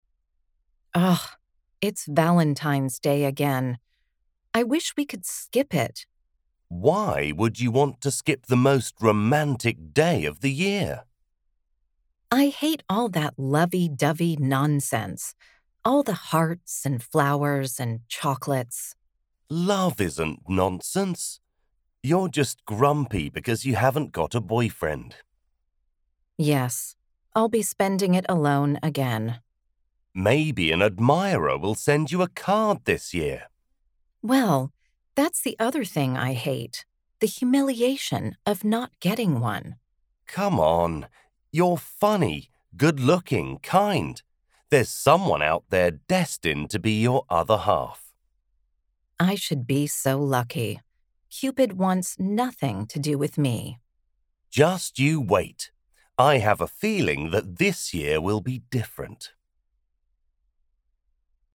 Speaker (UK accent)
Speaker (American accent)